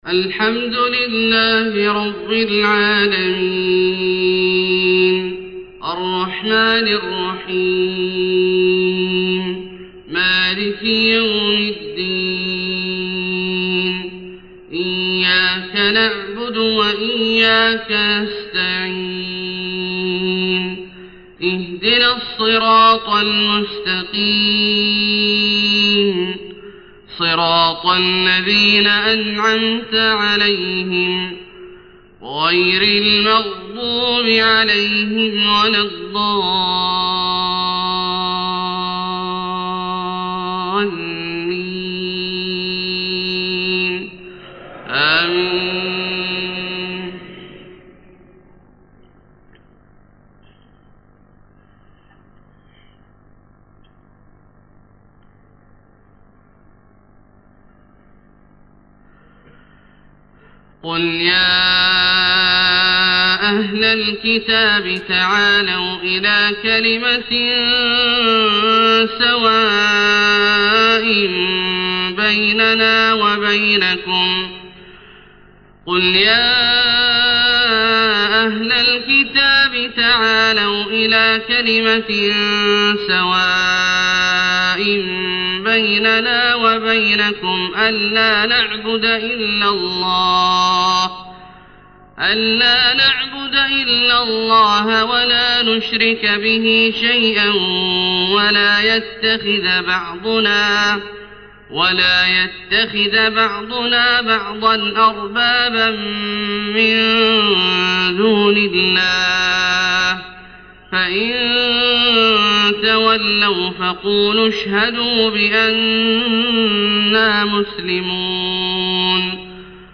Fajr Prayar from Surah Aal-i-Imraan | 2-7-2009 > 1430 H > Prayers - Abdullah Al-Juhani Recitations